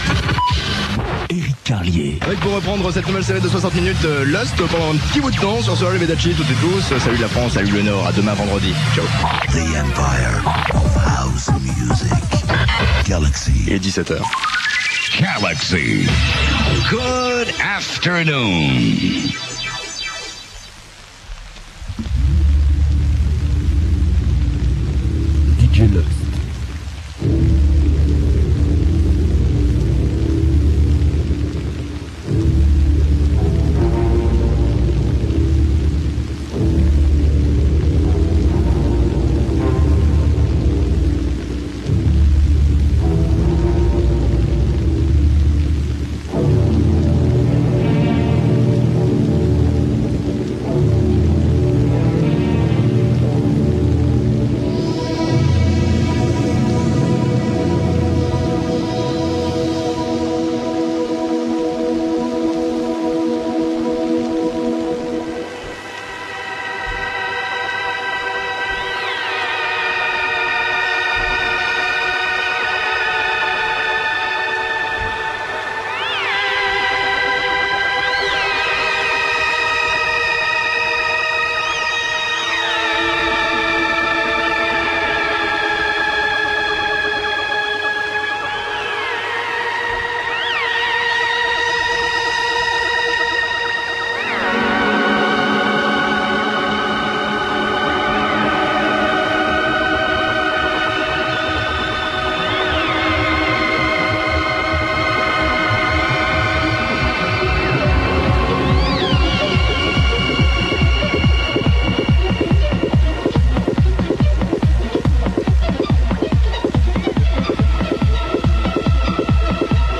Voil� une partie d'un set en direct
Un set assez calme